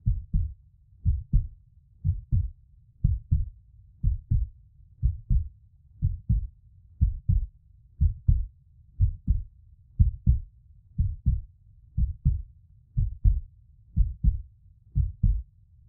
sfx_heart.mp3